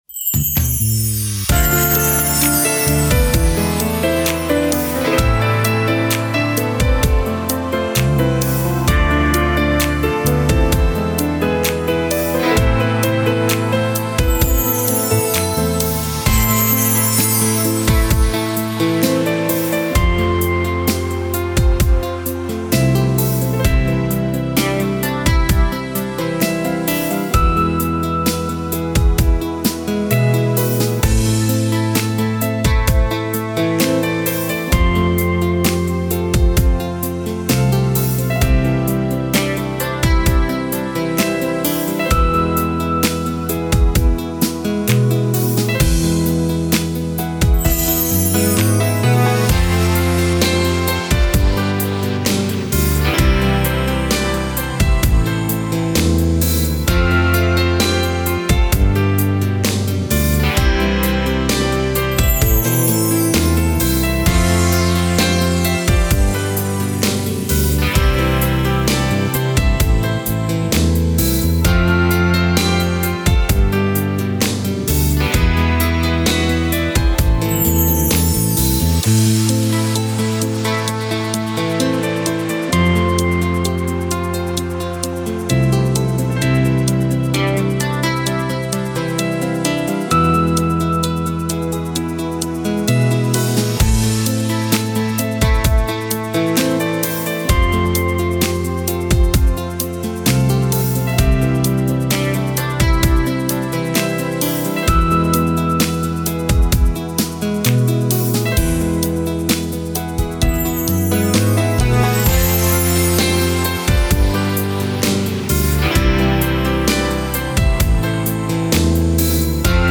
Детские